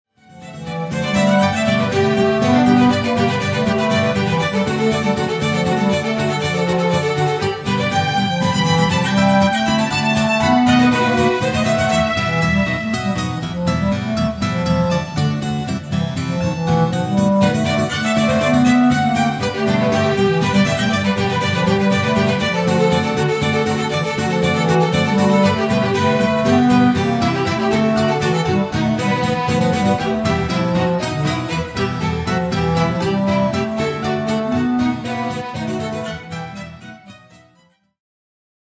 Gitarre, Flöte und eine Geige sind zu hören.